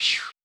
new chant.wav